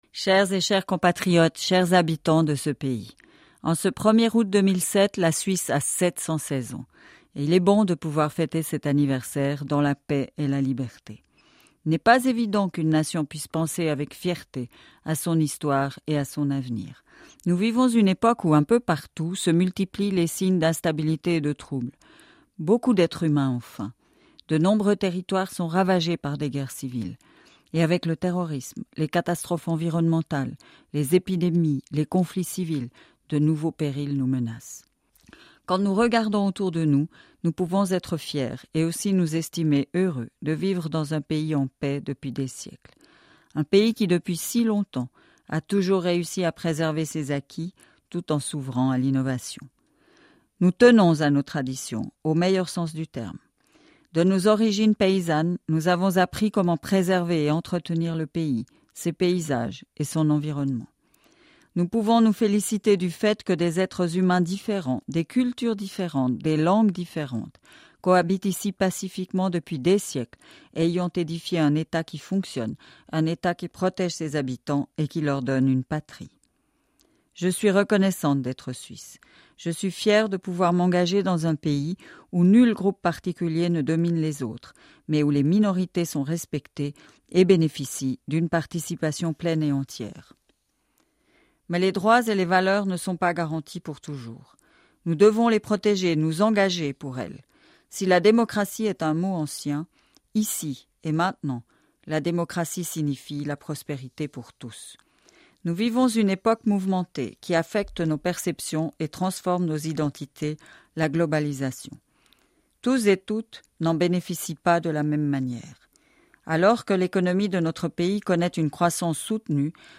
Le discours du 1er Août de Micheline Calmy-Rey
Micheline Calmy-Rey, présidente de la Confédération